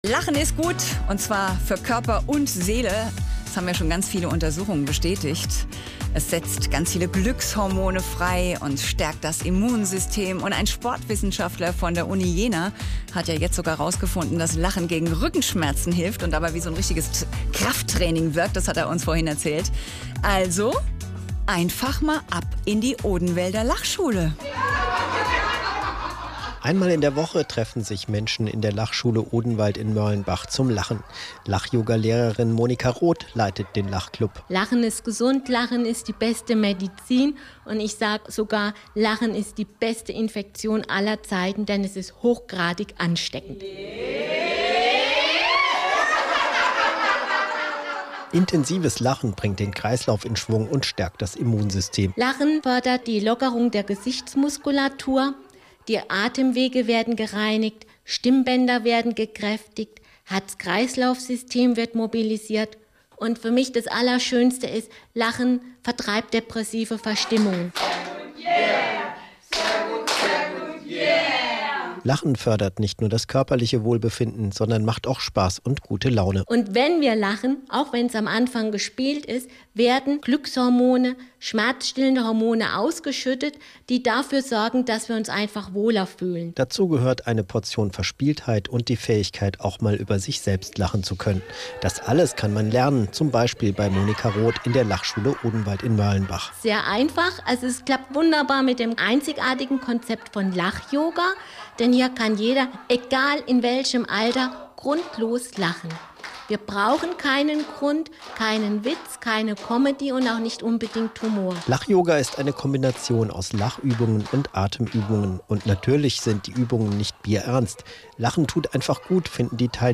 Hessischer Rundfunk zu Gast in der Lachschule im März 2015